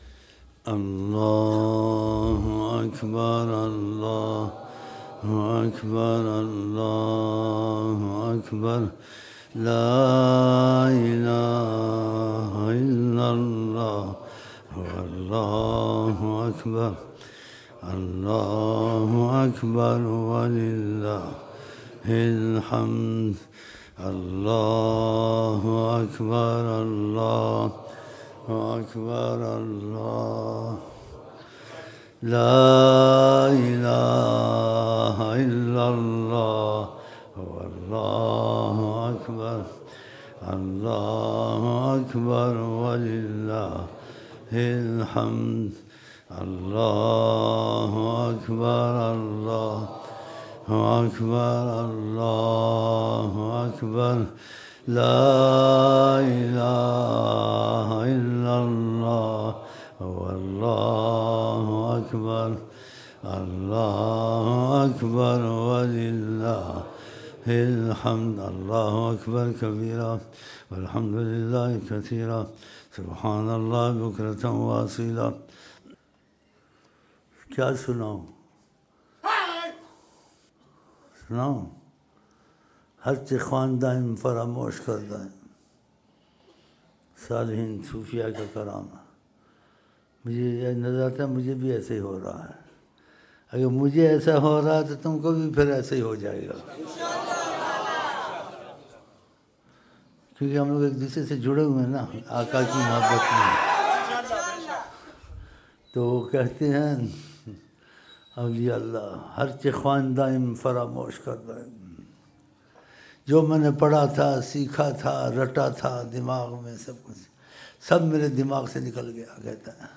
عصر محفل
Eid-ul-Azha Takbeerat